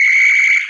Mario's horn from Mario Kart 8.
MK8_Mario_-_Horn.oga.mp3